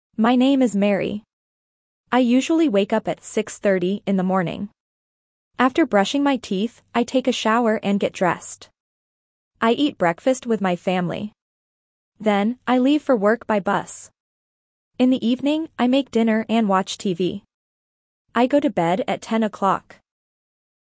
Dictation A2 – Daily Activities
1.-A2-Dictation-Daily-Activities.mp3